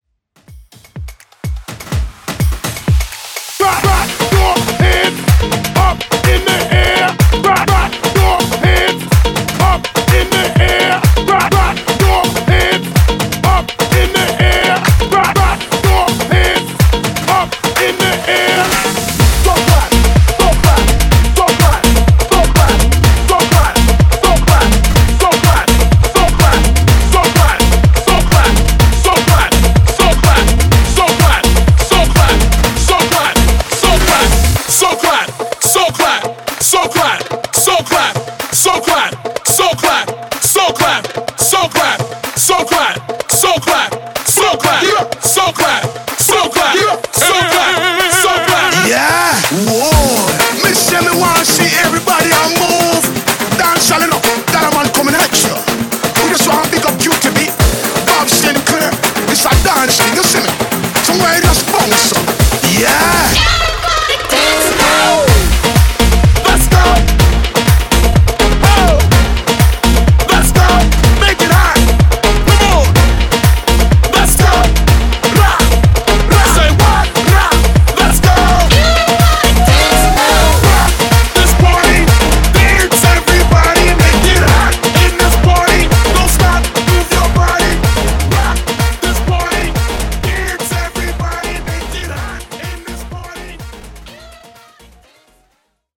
Hype Afro House)Date Added